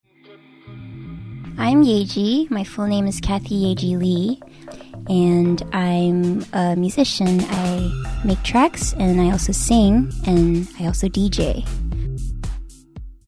イェジ （本人の発音はイージに近い）
本人による自己紹介